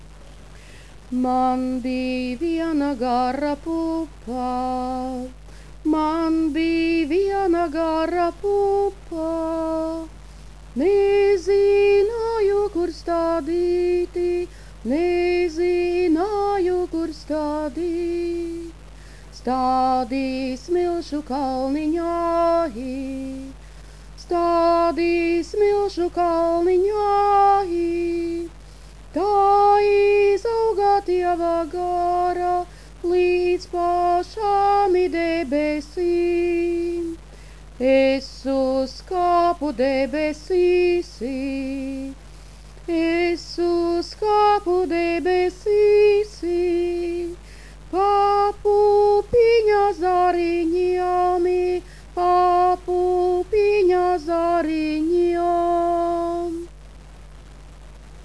who has a wonderful voice
a couple of tunes of a native folk’s song
please click here and listen - WAV-File) with us so we’d understand the phenomenal acoustics in these wonderful old buildings.